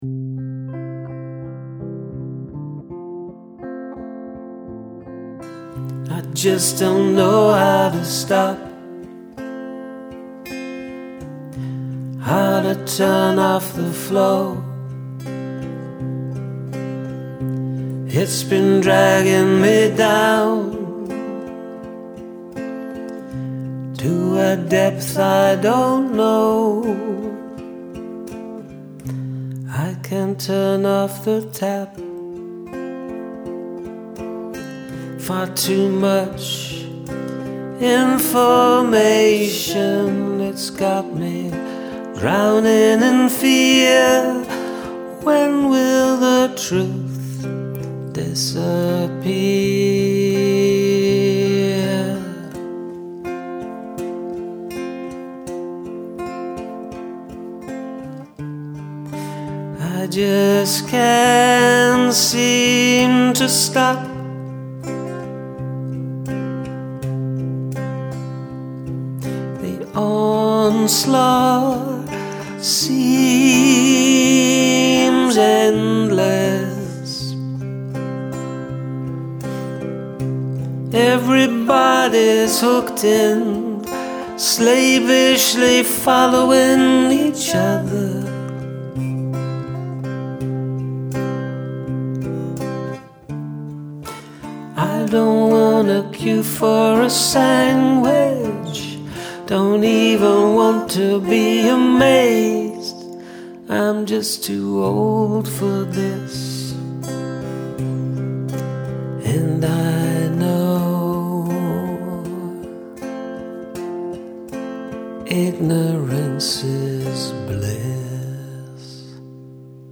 A sweeter one sound-wise with some harmonies here and there
Those beautiful harmonies leaven the sadness, somewhat.
another good acoustic track with bv.